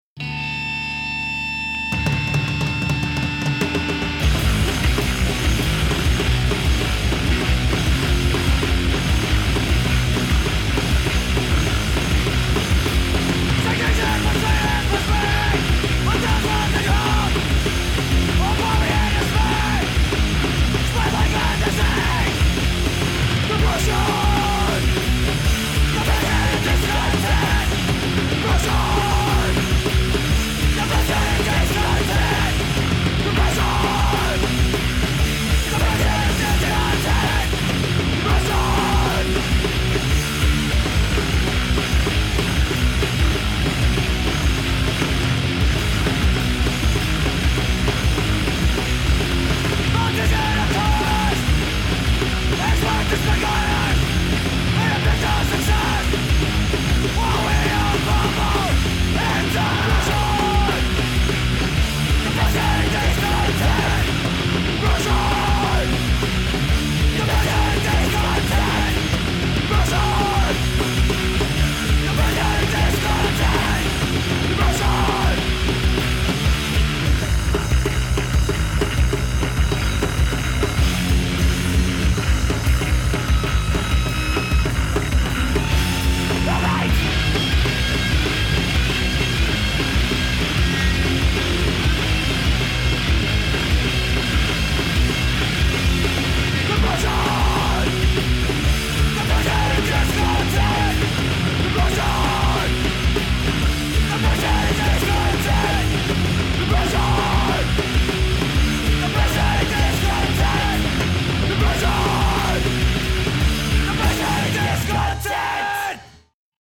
guitar, vocals
bass, vocals
drums